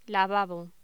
Locución: Lavabo